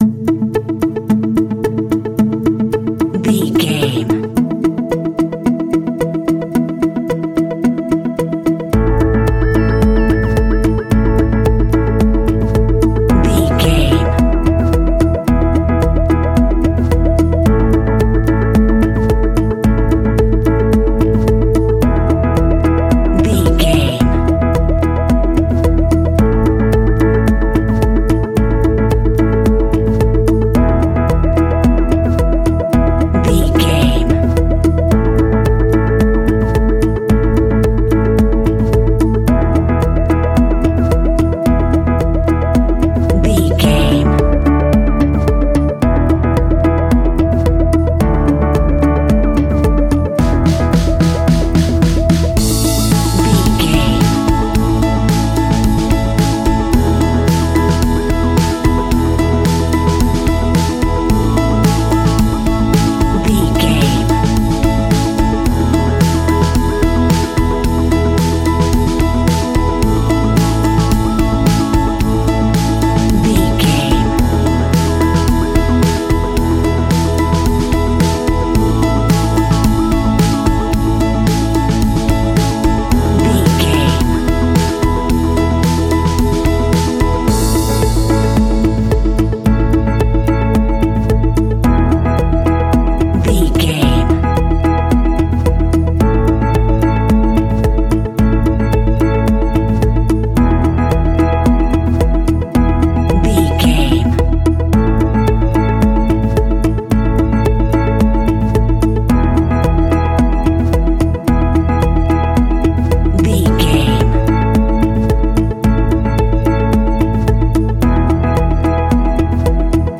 Ionian/Major
pop rock
energetic
uplifting
catchy
acoustic guitar
electric guitar
drums
piano
organ
electric piano
bass guitar